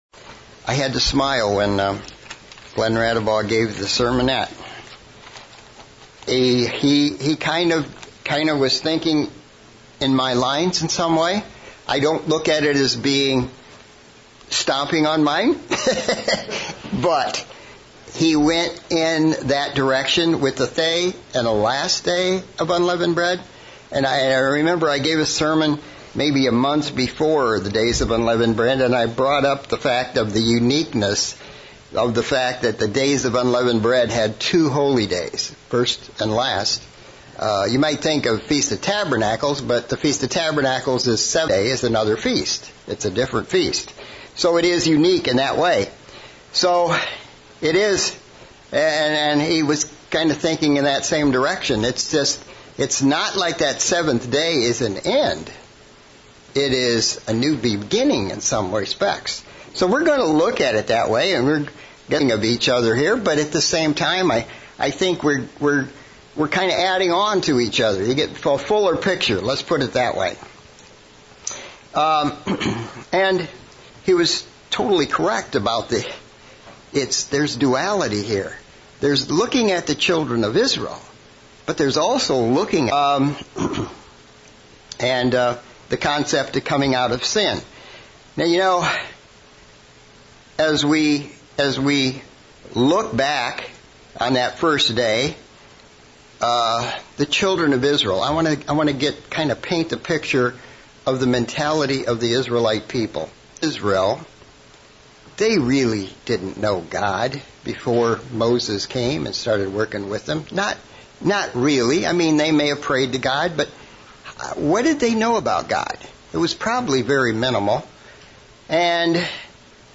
LDUB sermon looking at Israel's journey through the wilderness and where they fell short. 3 examples of mistakes they made that we should try hard to avoid.